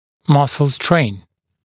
[‘mʌsl streɪn][‘масл стрэйн]напряжение мышц, мышечное напряжение